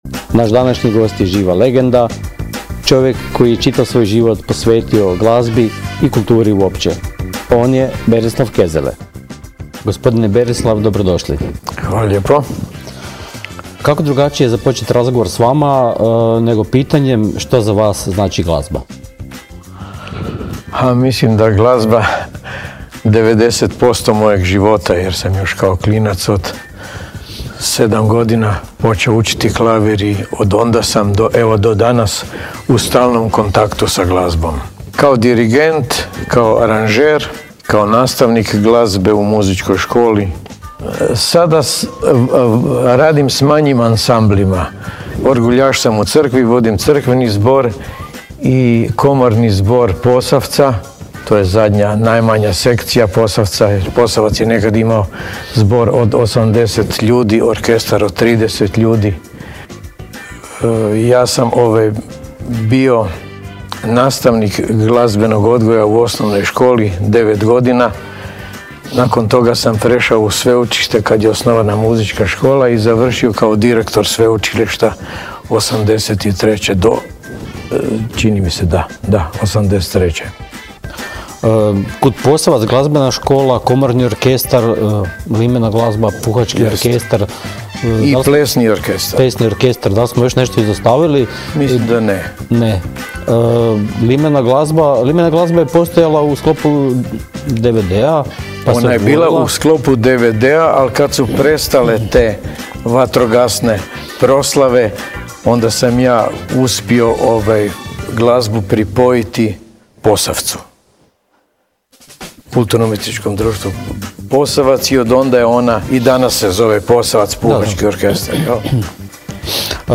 Originalni audio zapis razgovora